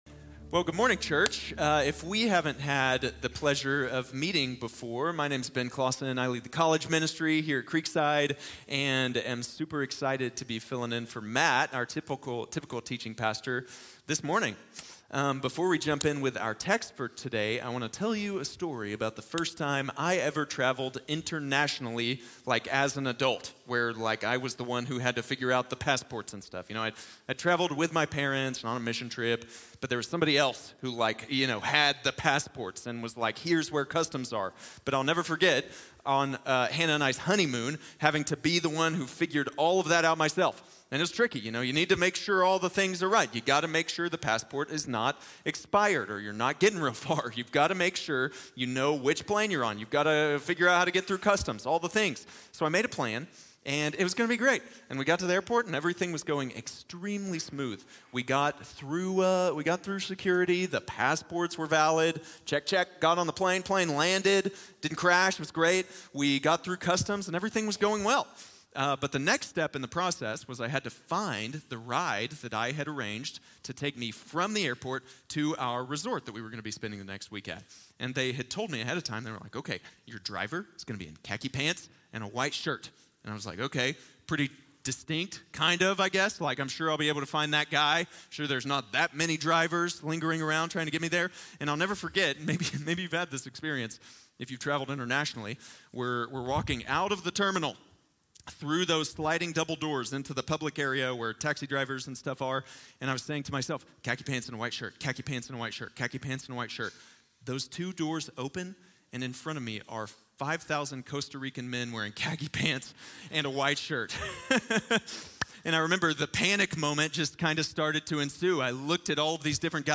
Jesus is the one you’re looking for | Sermon | Grace Bible Church